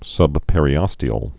(sŭbpĕr-ē-ŏstē-əl)